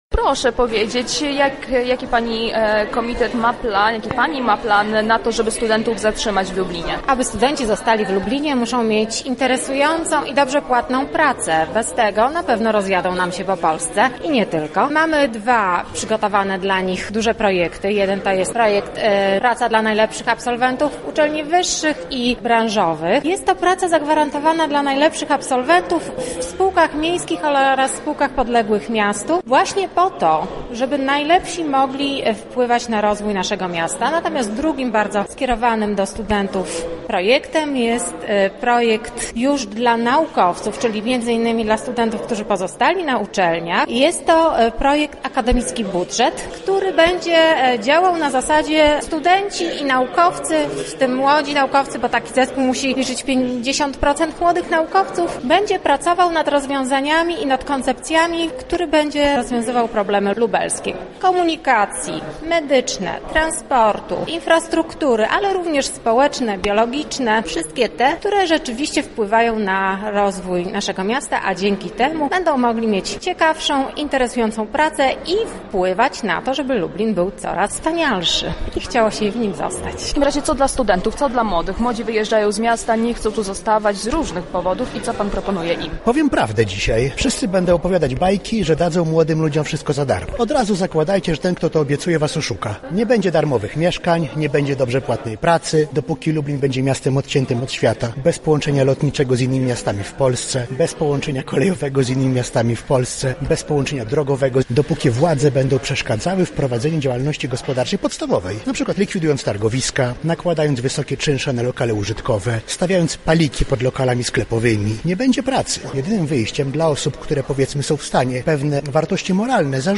Debata kandydatów na prezydenta na Wydziale Politologii Uniwersytetu Marii Curie-Skłodowskiej